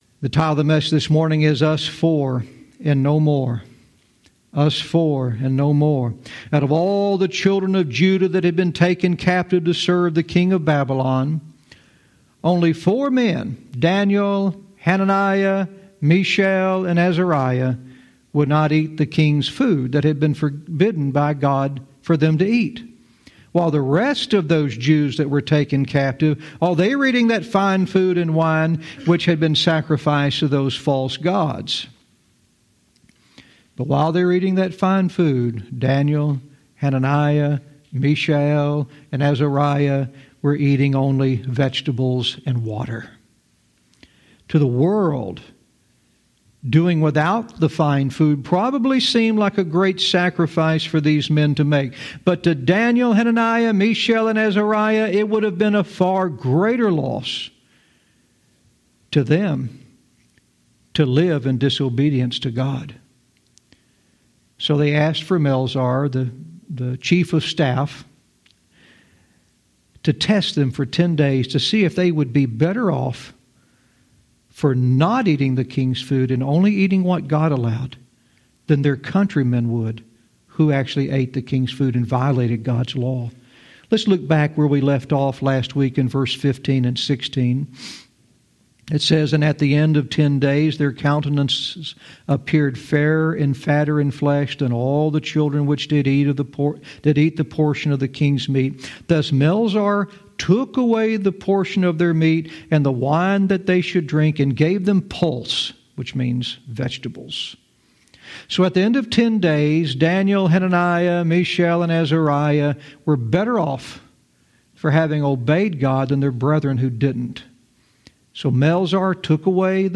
Verse by verse teaching - Daniel 1:17 "Us Four and No More"